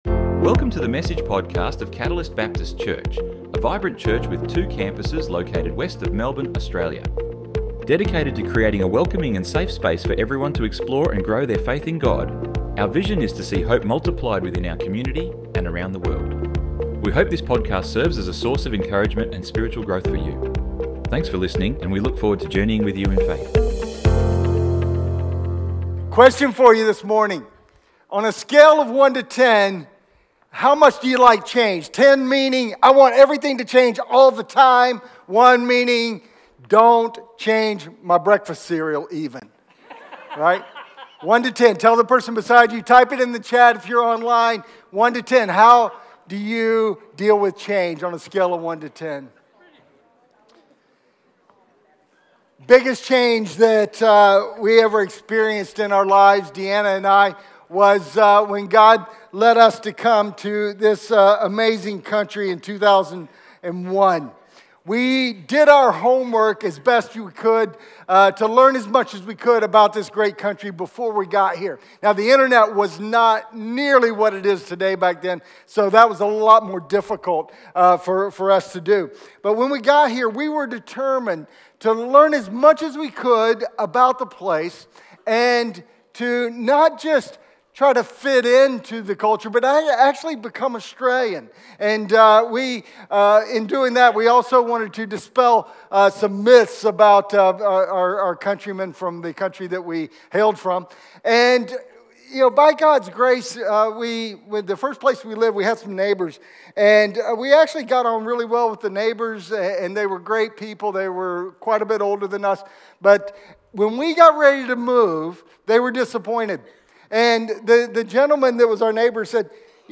Download Download Sermon Notes 04 - online notes - Be a Cataylst - learning and living - werribee.doc 04-LivingCatalyst-Practical-and-Providential-Study-Notes.pdf What does it mean to go deep with God?